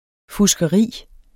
Udtale [ fusgʌˈʁiˀ ]